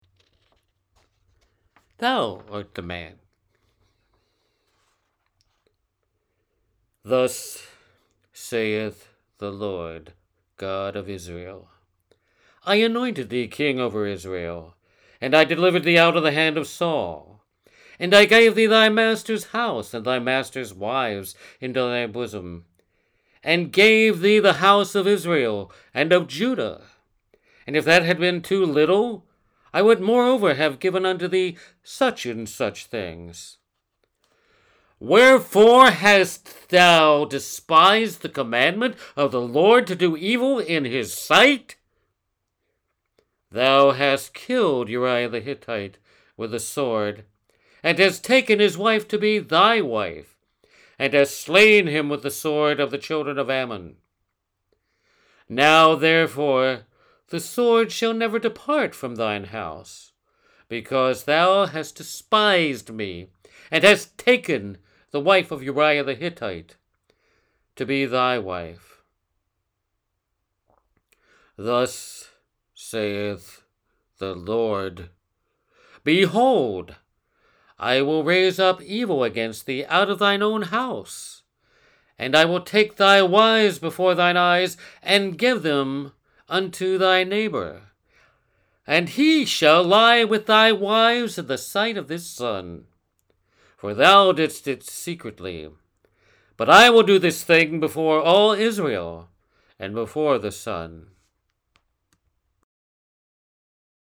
VOICE ACTING
Two recordings as the prophet Nathan in a biblical drama about King David.